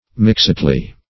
mixedly - definition of mixedly - synonyms, pronunciation, spelling from Free Dictionary Search Result for " mixedly" : The Collaborative International Dictionary of English v.0.48: Mixedly \Mix"ed*ly\, adv. In a mixed or mingled manner.